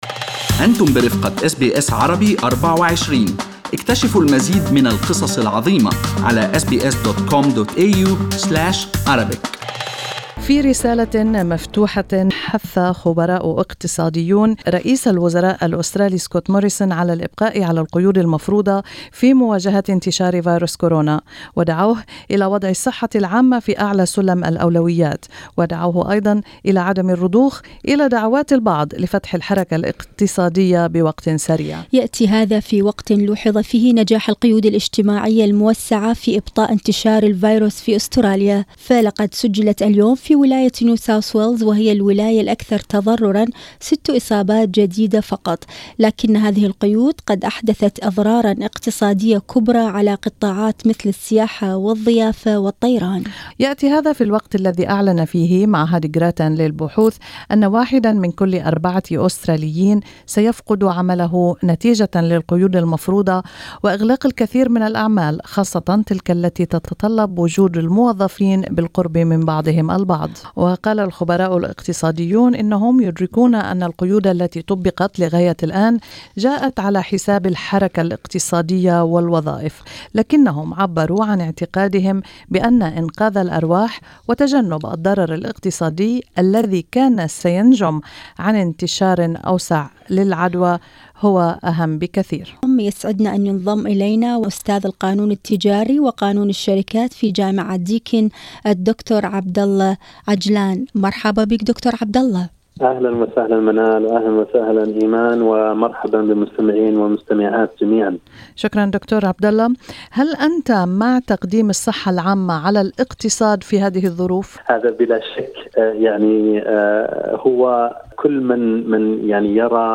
في حديث مع SBS Arabic24